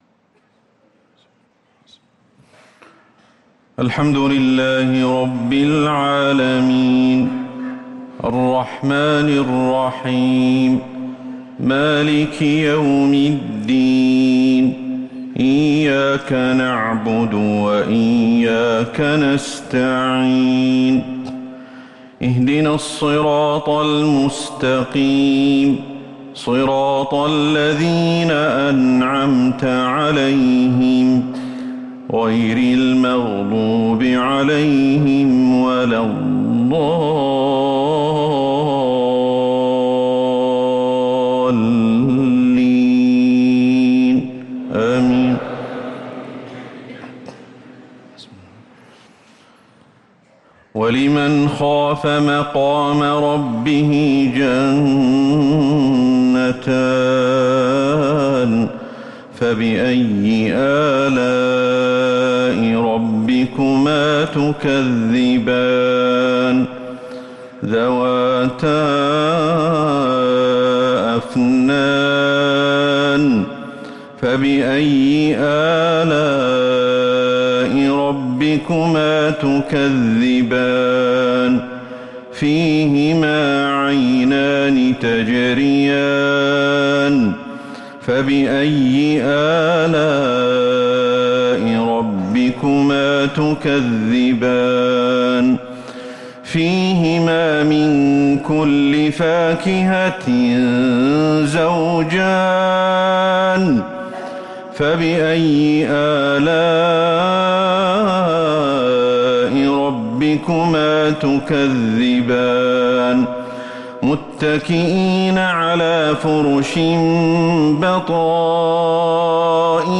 صلاة العشاء للقارئ أحمد الحذيفي 7 محرم 1445 هـ